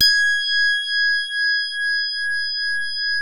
JAZZ HARD#G5.wav